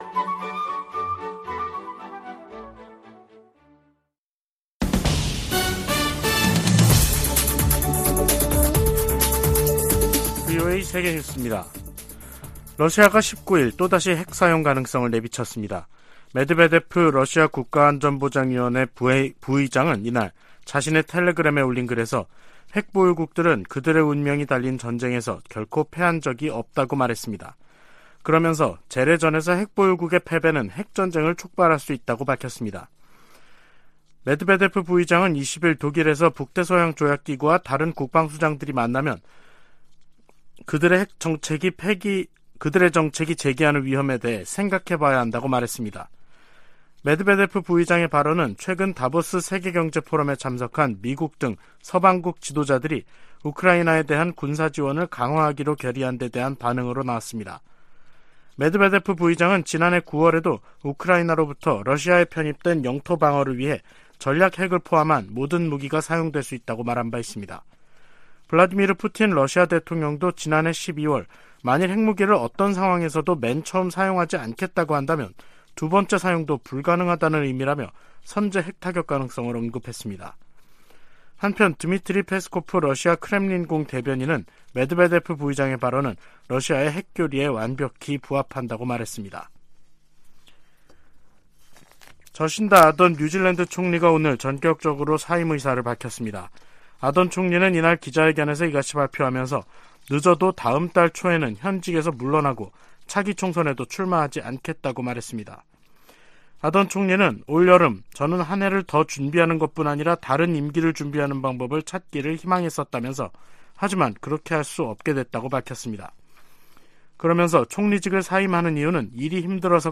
VOA 한국어 간판 뉴스 프로그램 '뉴스 투데이', 2023년 1월 19일 3부 방송입니다. 북한이 핵보유국을 자처해도 미국의 한반도 비핵화 목표에는 변함이 없다고 국무부가 밝혔습니다. 김정은 국무위원장이 불참한 가운데 열린 북한 최고인민회의는 경제난 타개를 위한 대책은 보이지 않고 사상 통제를 강화하는 조치들을 두드러졌다는 분석이 나오고 있습니다.